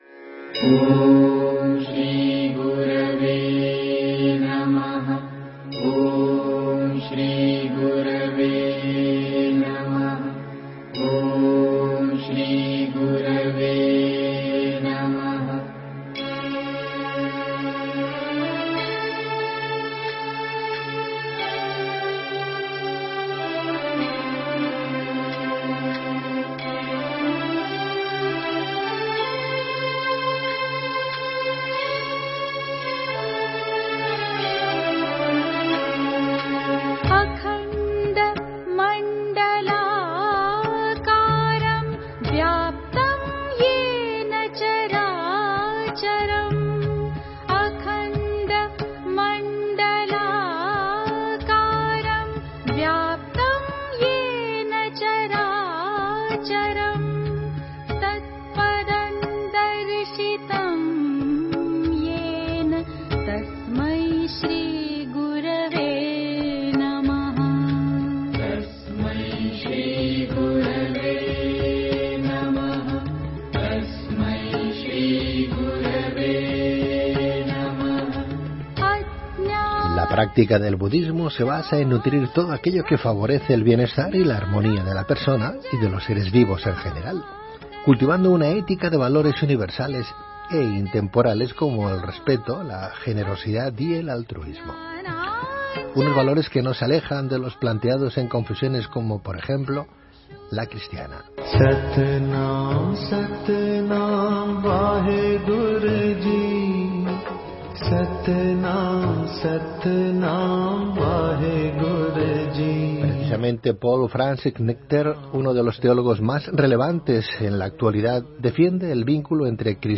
Audio de la presentación de ‘Sin Buda no podría ser cristiano’ en Barcelona